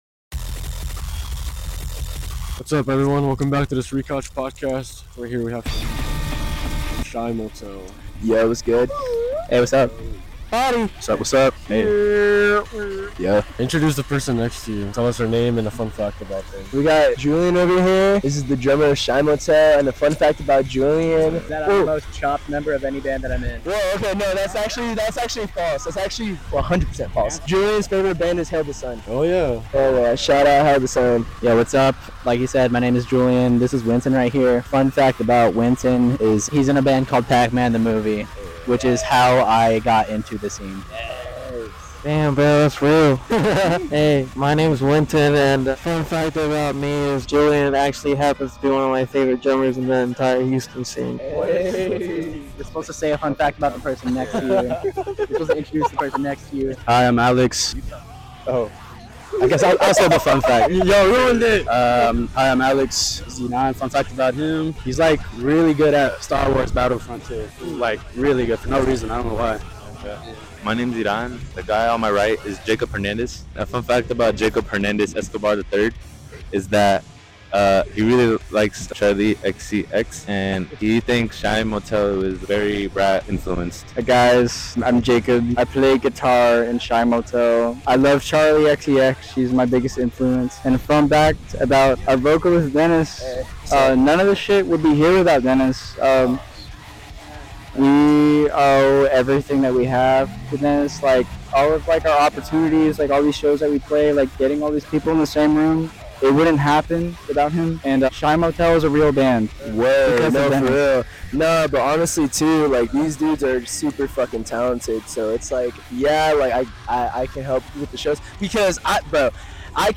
THE STREET COUCH #53 shymotel I interviewed shymotel after their set at Bad Astronaut on September 19, 2025. They are a supergroup that consists members from the bands EXOSSI, Pacmanthemovie, Cable, and oureyesgoblanktonight.